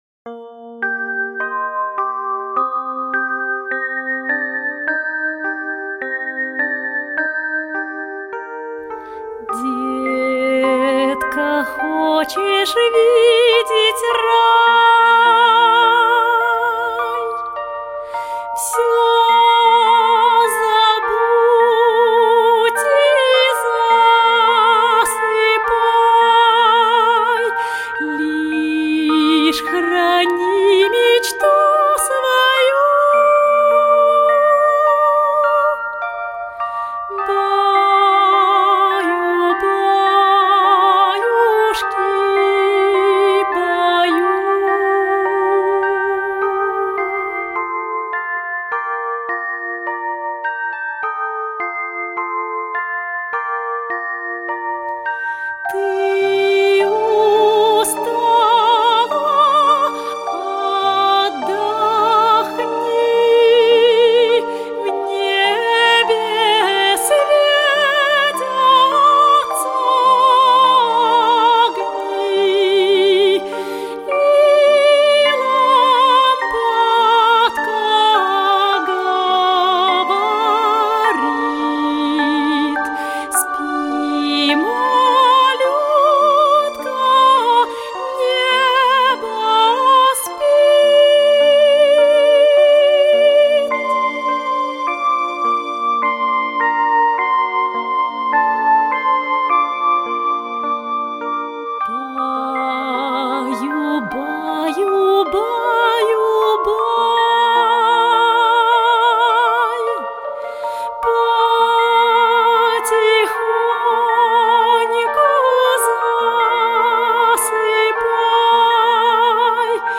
Аудиокнига Спи, малютка | Библиотека аудиокниг